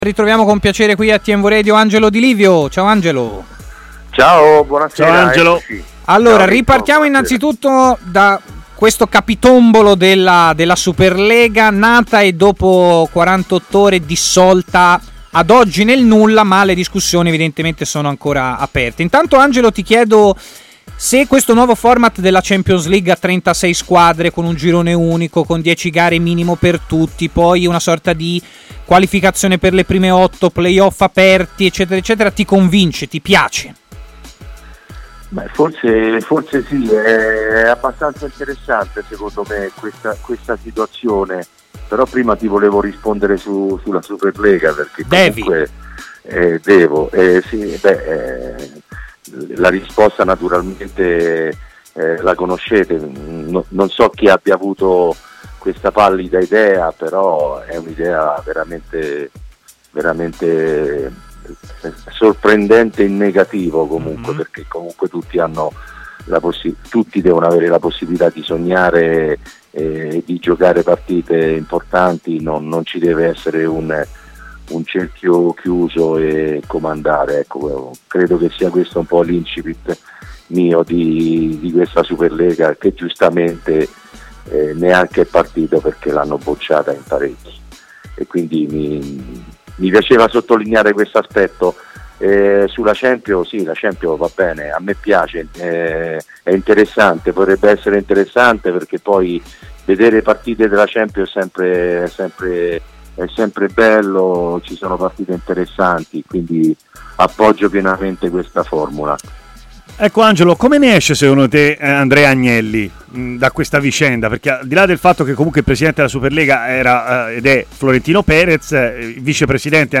è intervenuto in diretta a Stadio Aperto